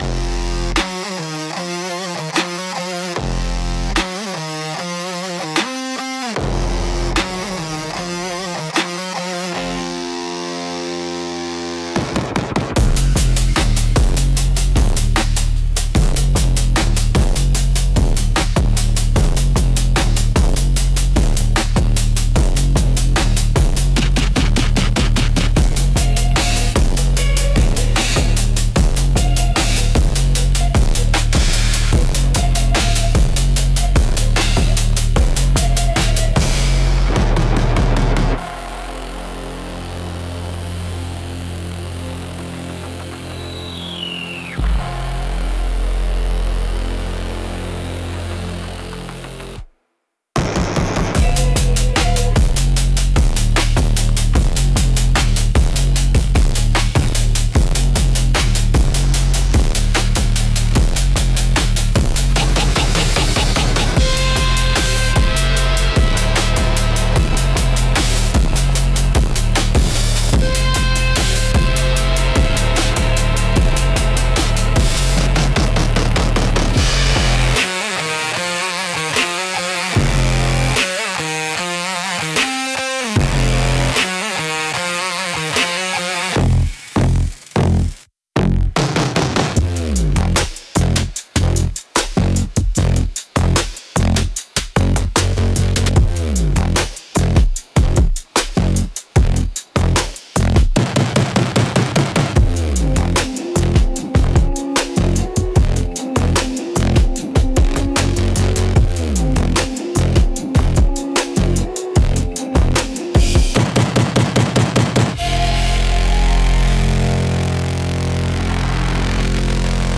Instrumental - Cinematic Southern Gothic x 808 Bass